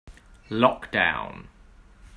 音声（イギリス）つきなので、まねして発音してみてください。